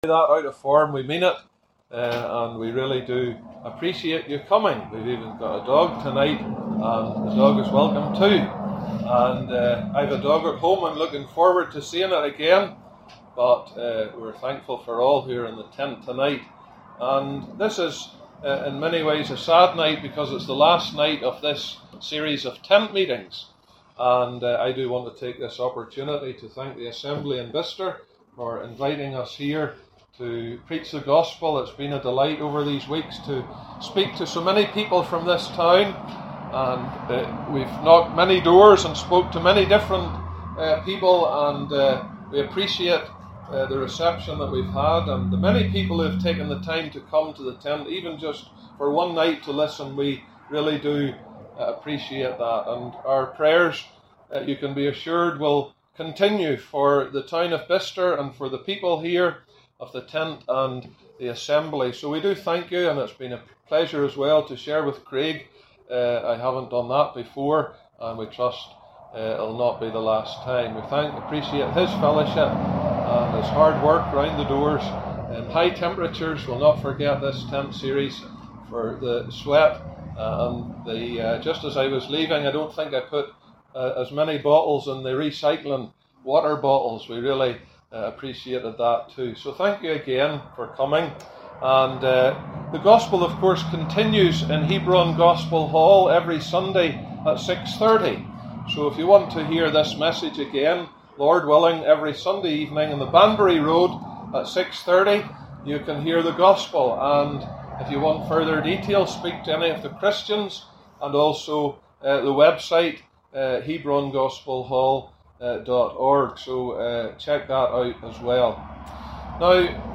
Gospel Tent ’25: What must I do to be saved? (28 mins)